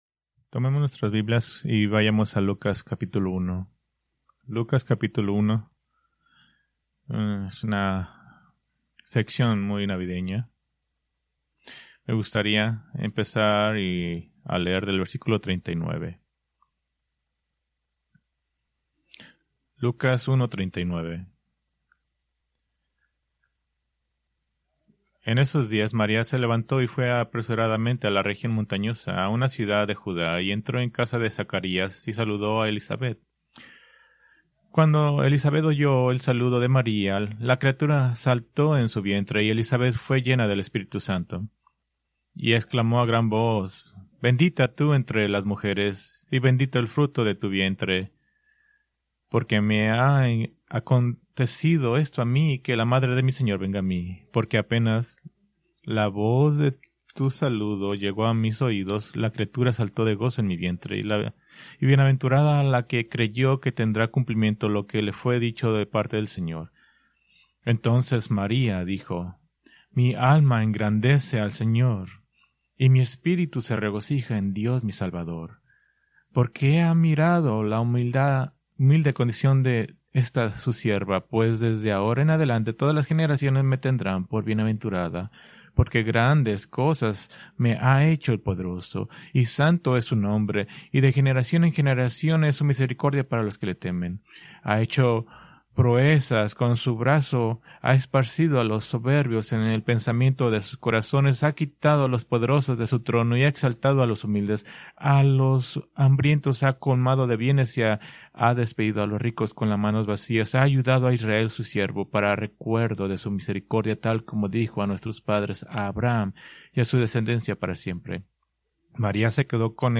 Preached November 30, 2025 from Lucas 1:46-47